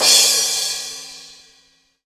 Crash_Cym_2.wav